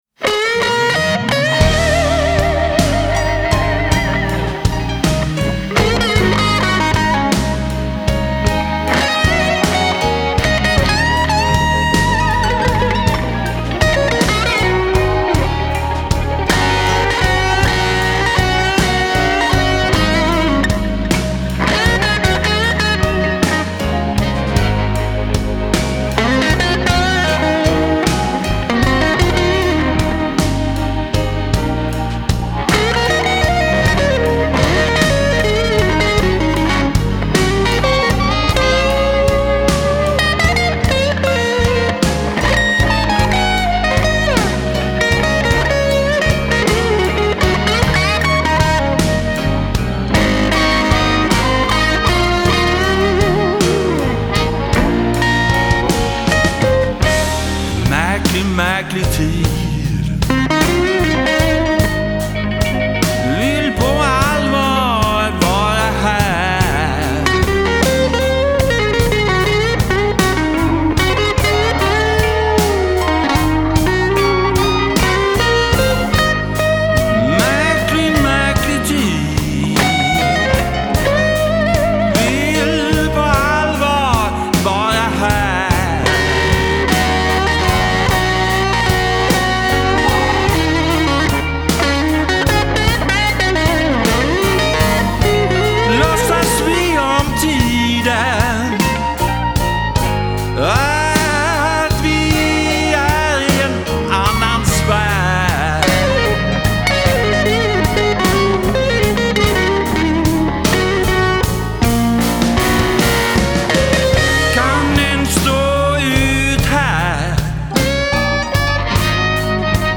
Спасибо, классный блюз!